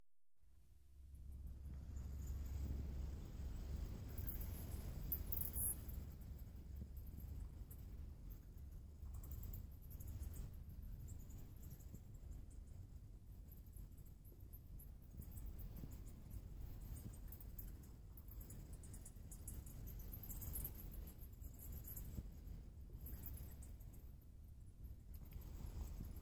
We found a sizable colony of bats in this cave.  We couldn’t get a photo of them, but there is an audio recording of their chattering at the bottom of today’s post:
Here’s the audio recording of the bats we found today in the cave:
bats.mp3